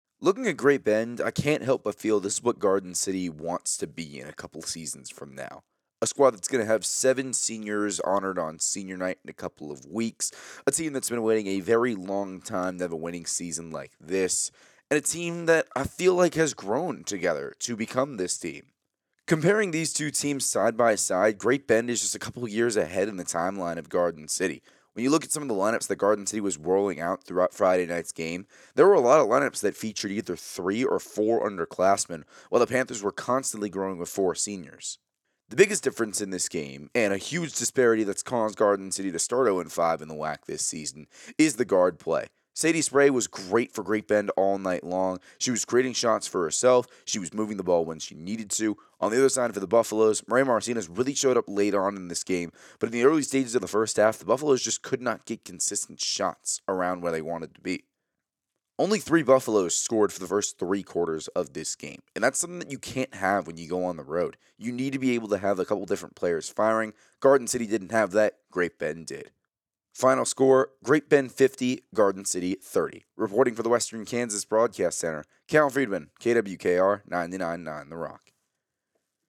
Audio Recap